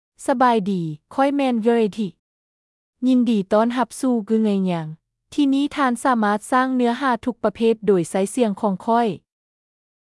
FemaleLao (Laos)
KeomanyFemale Lao AI voice
Keomany is a female AI voice for Lao (Laos).
Voice sample
Female